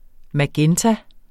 Udtale [ maˈgεnta ]